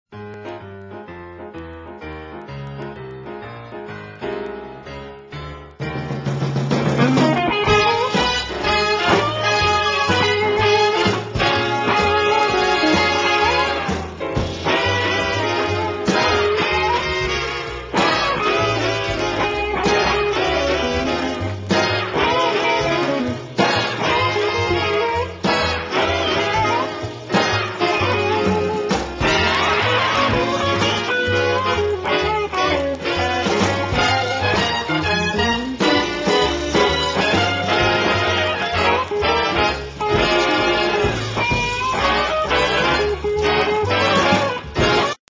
BLUES KARAOKE MUSIC CDs